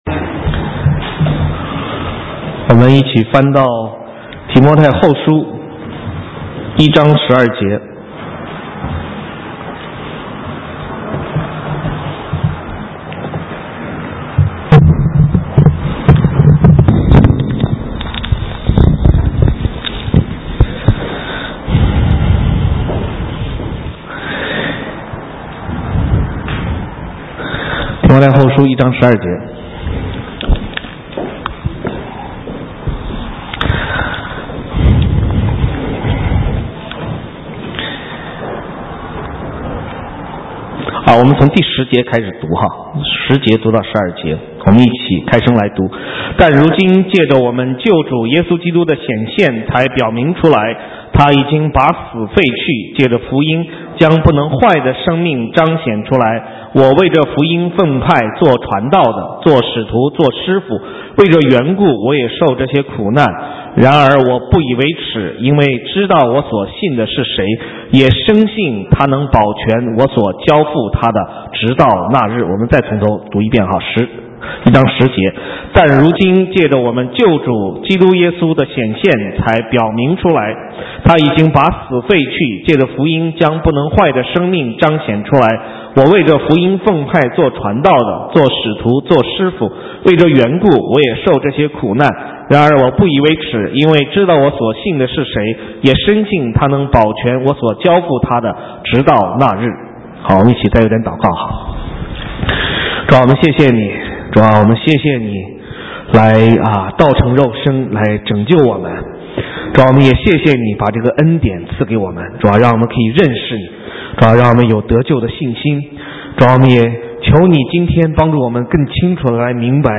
神州宣教--讲道录音 浏览：认识耶稣基督是谁 (2011-04-10)